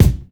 • 00s Snappy Bass Drum F Key 08.wav
Royality free bass drum sound tuned to the F note. Loudest frequency: 606Hz
00s-snappy-bass-drum-f-key-08-XsC.wav